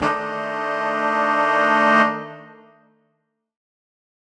UC_HornSwell_Bminb5.wav